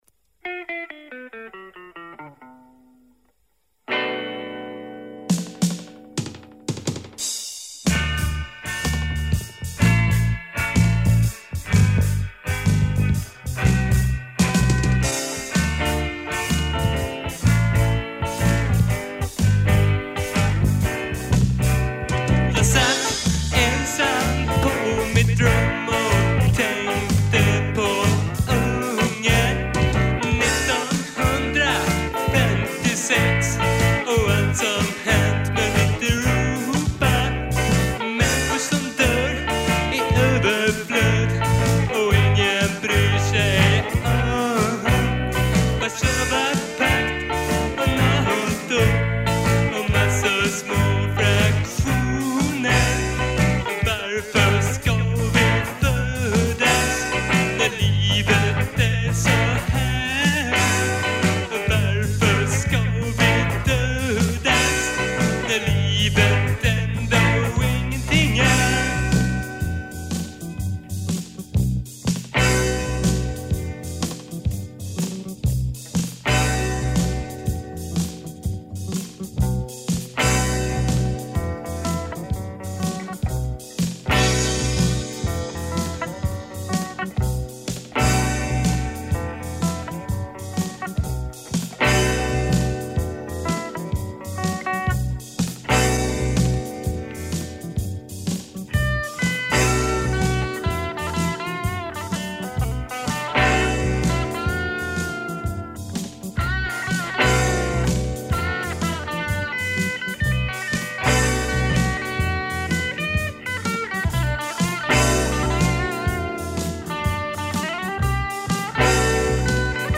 Guitar, voice
Bass
Drums
Keyboard